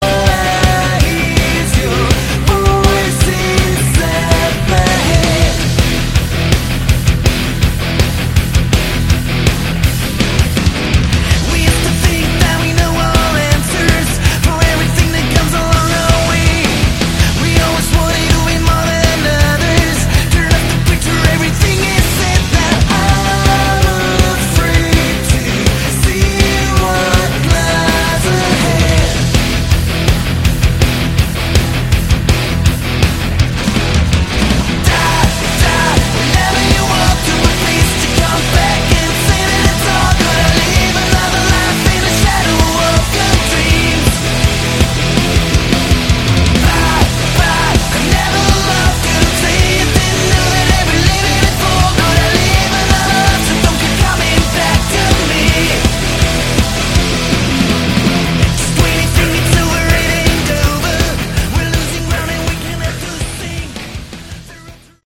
Category: Modern Synth Hard Rock